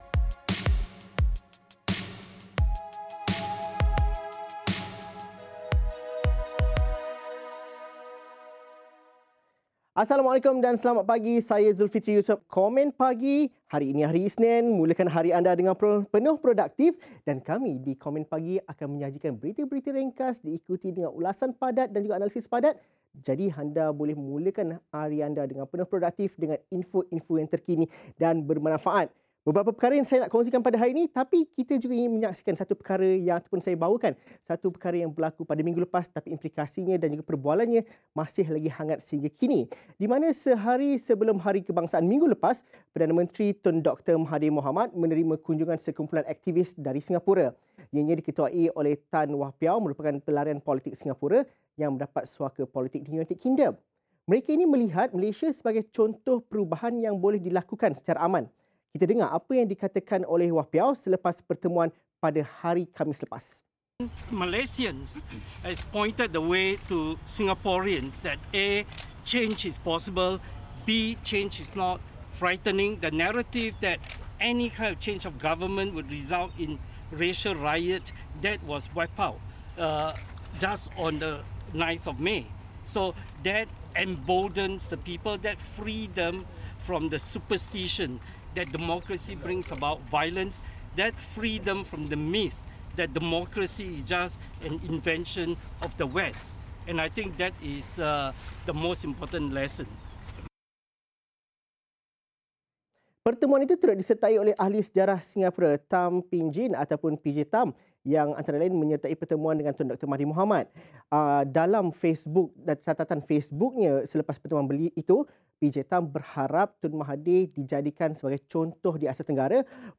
Berita dan analisis ringkas dan padat untuk anda memulakan hari anda dengan info-info yang bermanfaat.